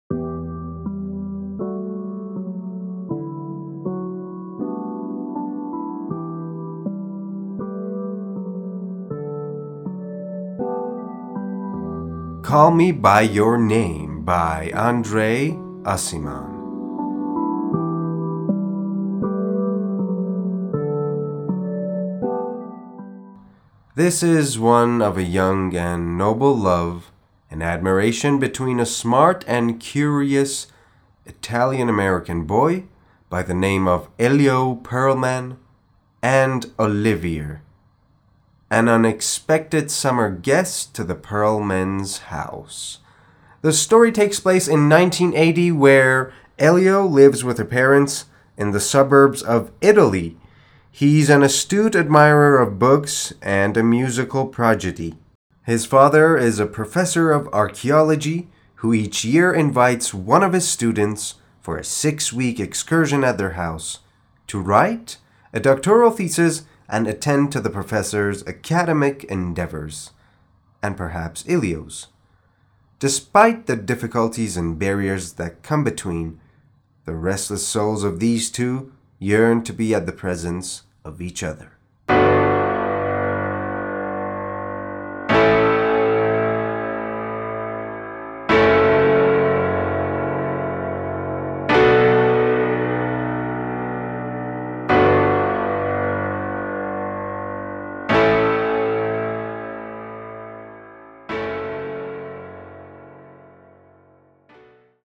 معرفی صوتی کتاب Call Me by Your Name